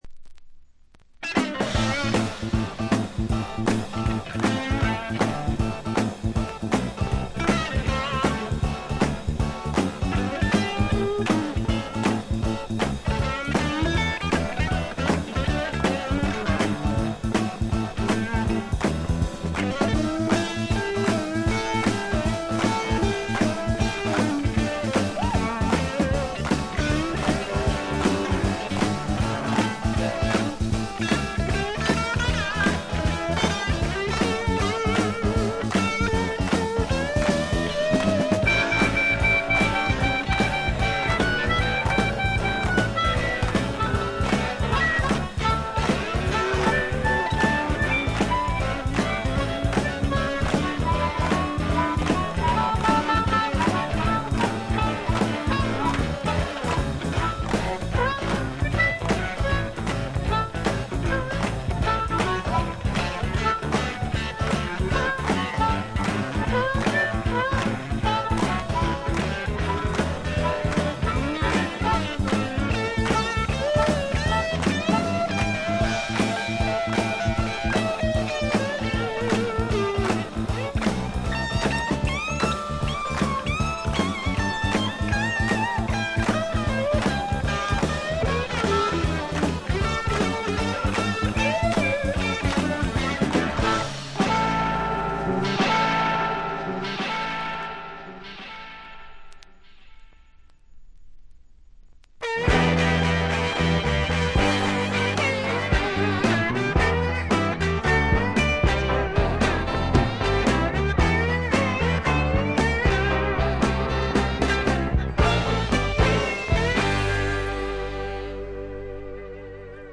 微細なバックグラウンドノイズのみでほとんどノイズ感無し。
よりファンキーに、よりダーティーにきめていて文句無し！
試聴曲は現品からの取り込み音源です。